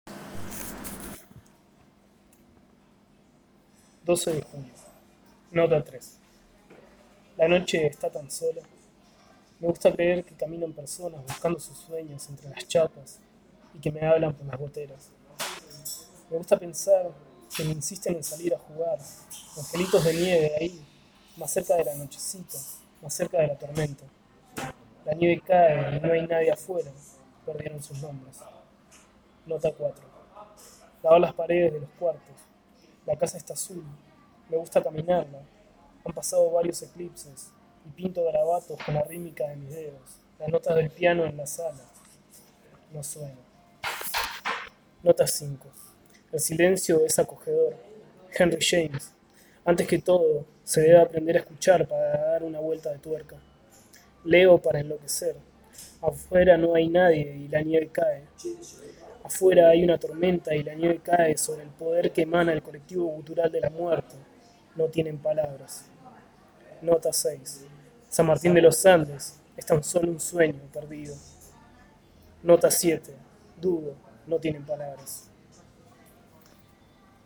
Fragmento de Notas de Invierno en la voz de su autor: